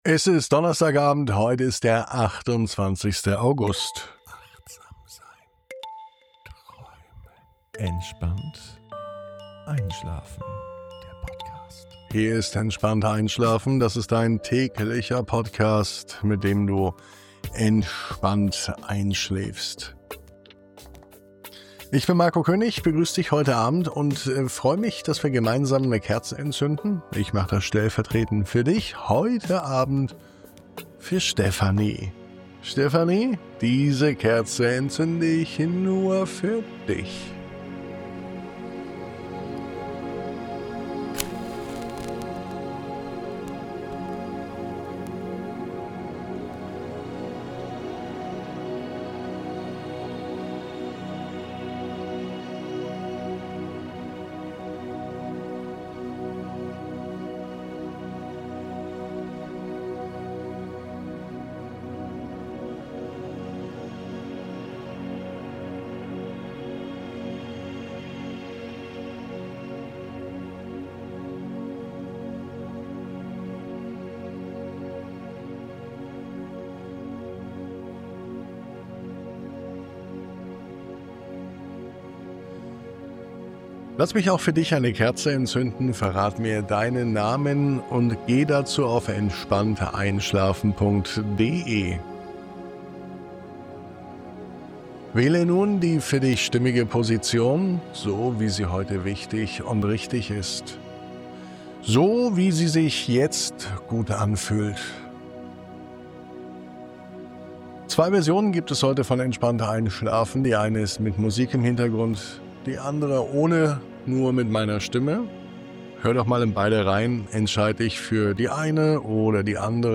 In dieser Folge begleiten dich ruhige Bilder einer Wildblumenwiese am Waldrand – voller kleiner Bewegungen, zarter Düfte und der friedlichen Geräusche eines Tages, der sich langsam verabschiedet. Gräser wiegen sich, der Wind flüstert durch die Bäume, und du darfst einfach nur beobachten… und loslassen. Eine Einladung zum Einschlafen – getragen von der Stille der Natur.